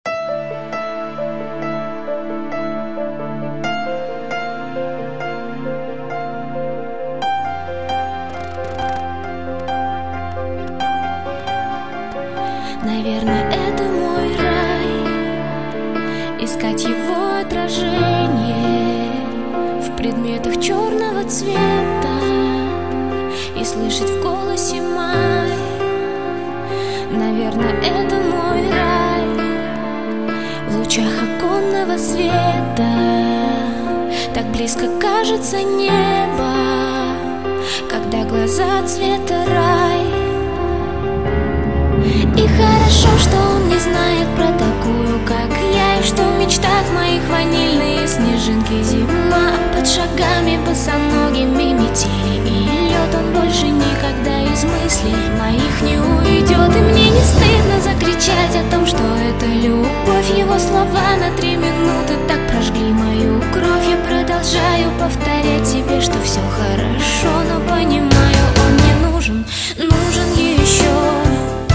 Pop
бас гитара
барабаны
композиции в стиле босса нова, и песни с  настроением рока.